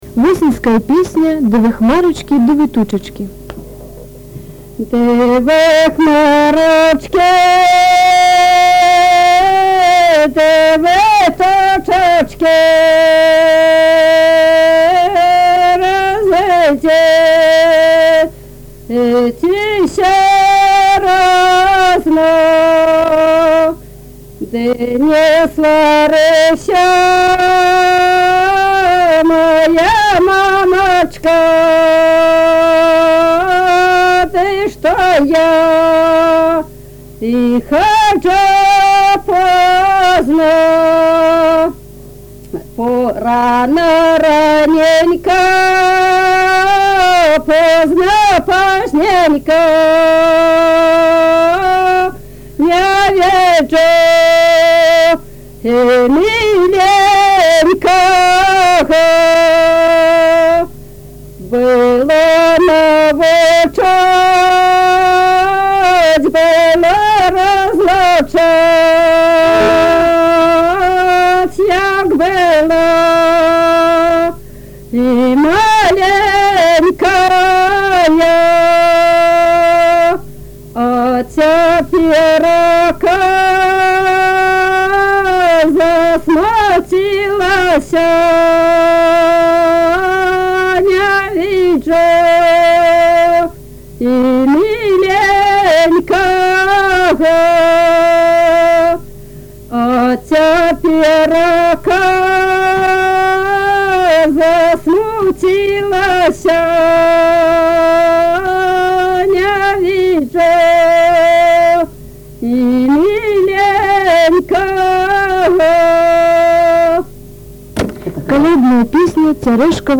Тема: ЭБ БГУ::Беларускі фальклор::Каляндарна-абрадавыя песні::восеньскія песні
Месца запісу: Узбішчы